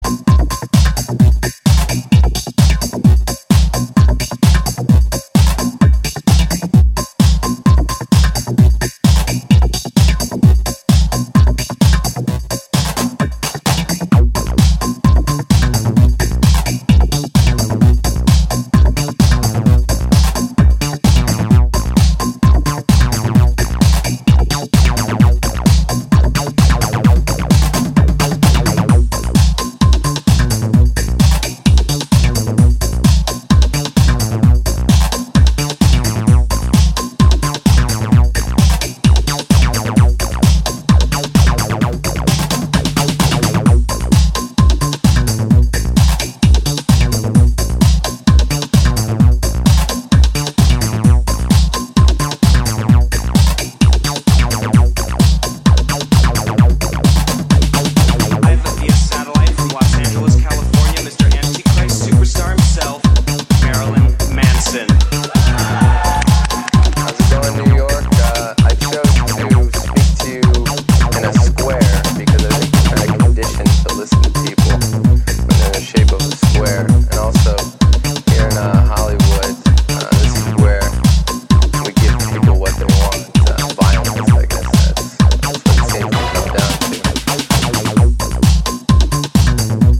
全体的に意識されていそうなミニマルな展開が引力を高めています。